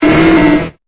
Cri de Maraiste dans Pokémon Épée et Bouclier.